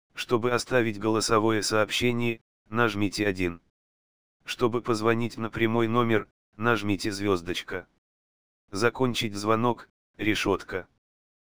голосовое меню_001 (online-audio-converter